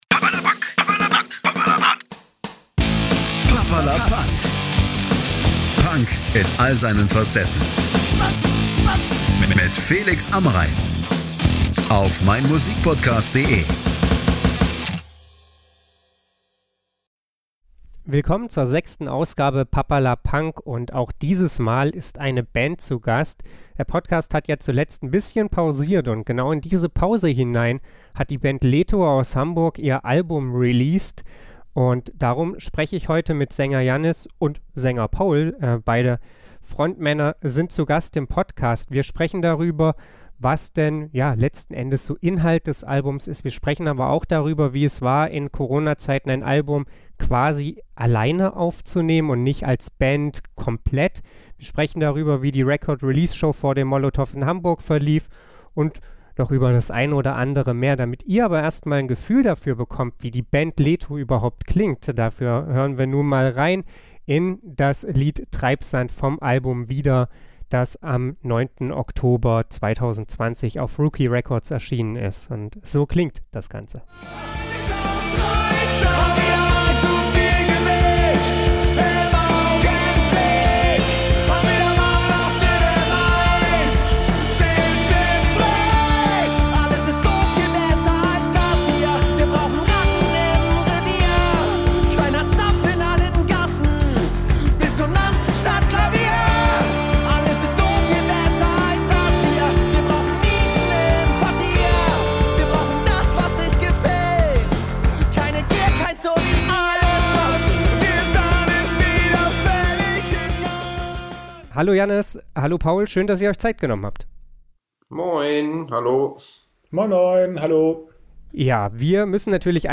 Bands/Künstler*innen im Podcast (° mit Musik)